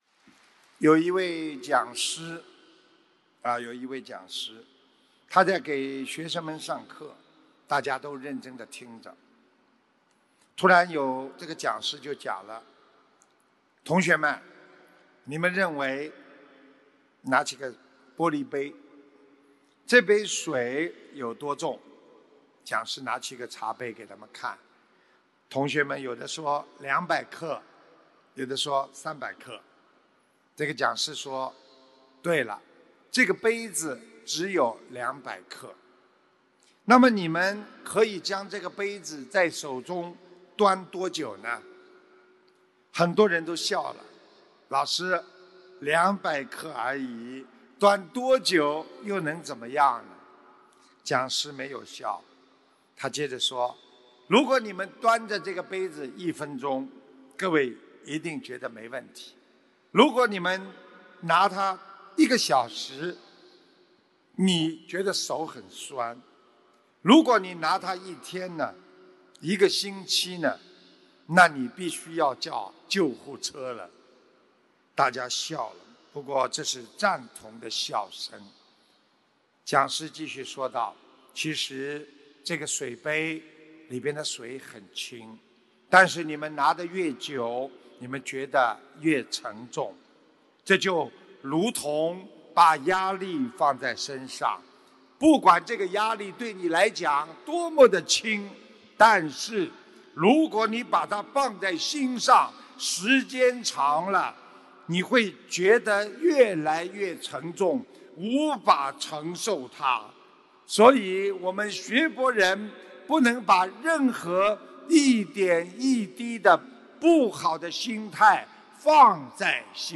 2017年8月25日马来西亚吉隆坡世界佛友见面会！